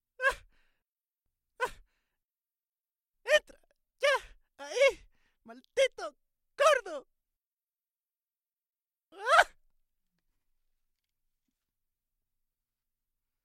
描述：Mujerquejándose。 Grabado con ZoomH4n
Tag: Berinche 女人 Queja